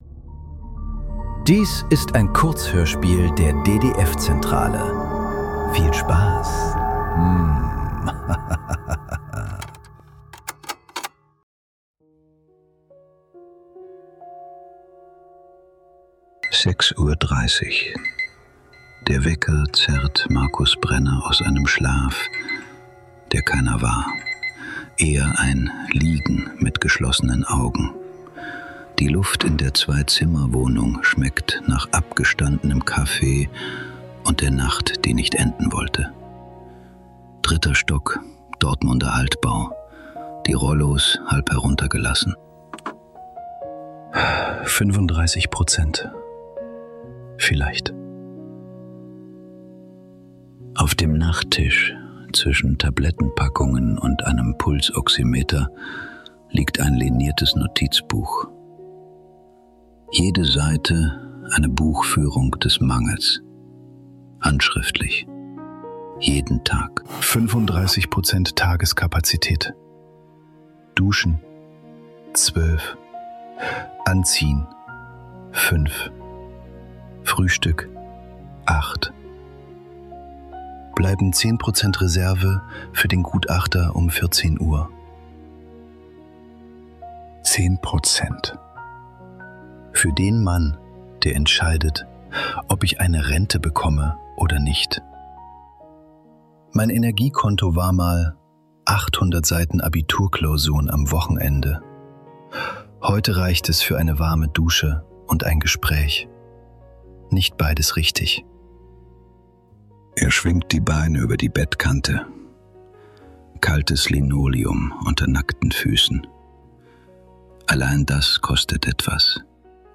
Fünfunddreißig Prozent ~ Nachklang. Kurzhörspiele.